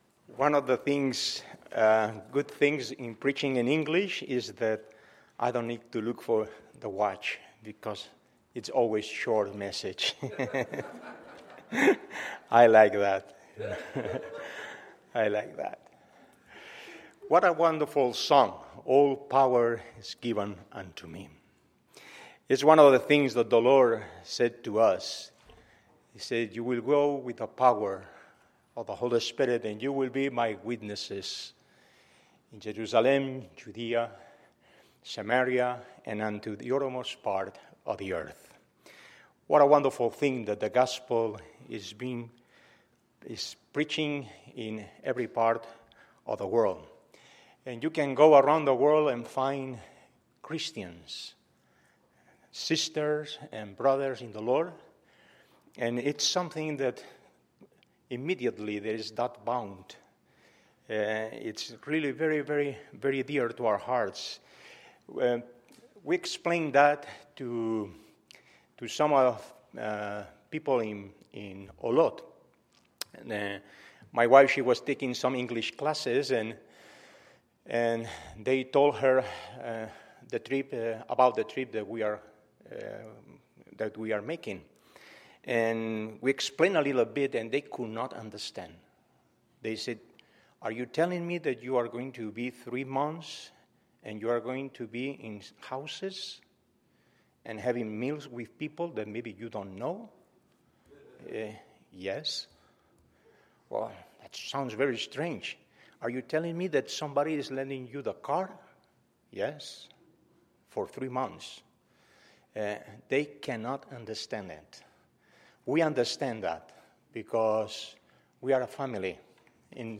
Tuesday, September 27, 2016 – 2016 Missions Conference – Tuesday Evening Session
Sermons